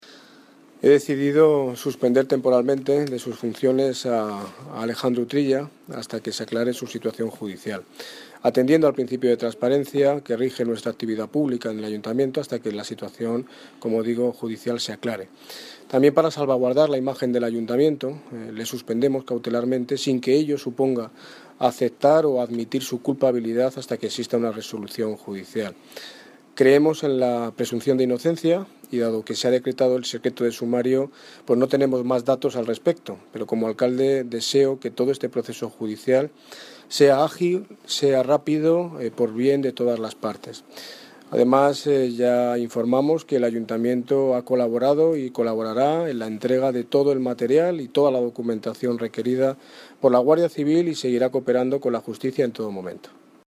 Audio - Daniel Ortiz (Alcalde de Mostoles) Sobre cese cautelar concejal
Audio - Daniel Ortiz (Alcalde de Mostoles) Sobre cese cautelar concejal.mp3